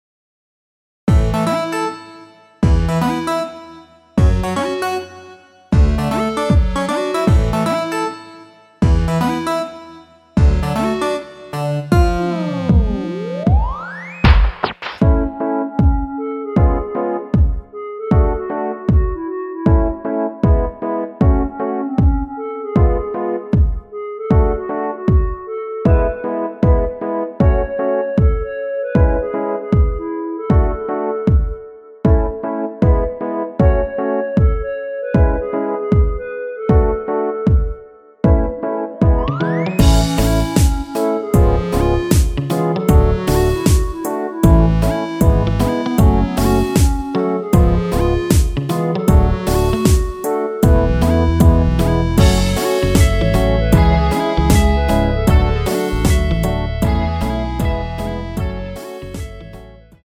원키에서(-1)내린 멜로디 포함된 MR입니다.
앞부분30초, 뒷부분30초씩 편집해서 올려 드리고 있습니다.
중간에 음이 끈어지고 다시 나오는 이유는